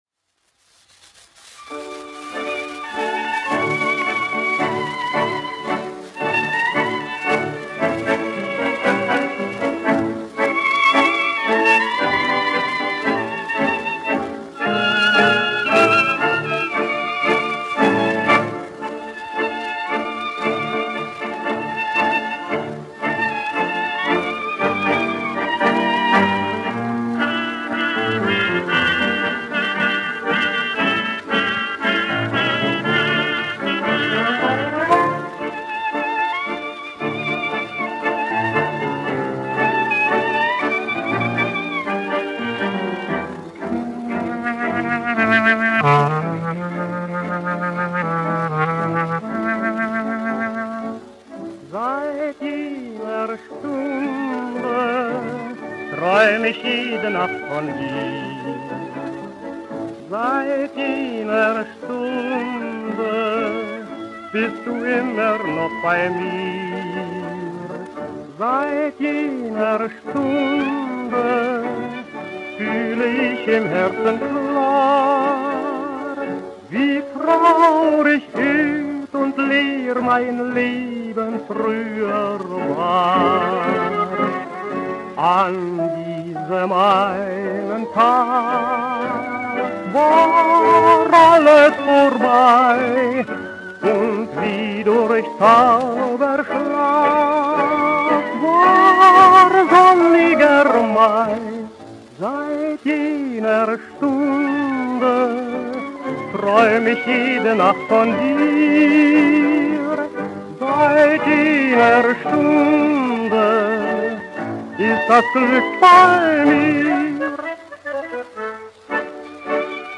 1 skpl. : analogs, 78 apgr/min, mono ; 25 cm
Kinomūzika
Latvijas vēsturiskie šellaka skaņuplašu ieraksti (Kolekcija)